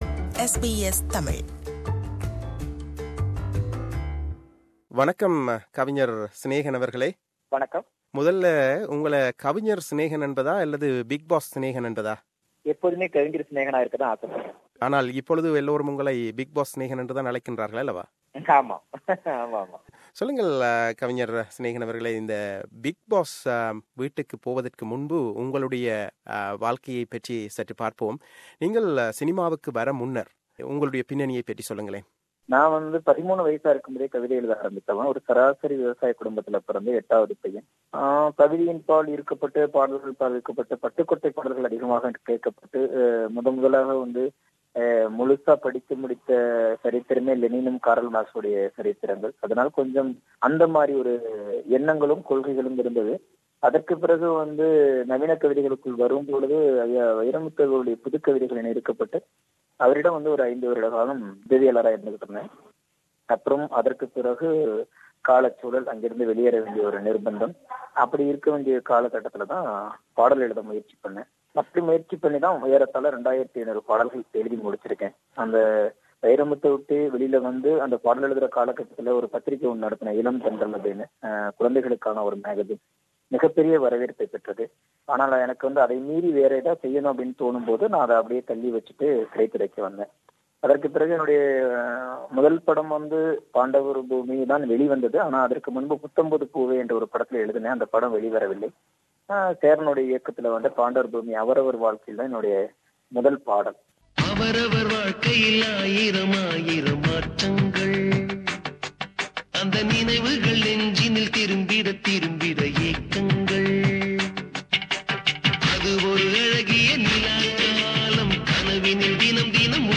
Lyricist Snehan - Interview Part 2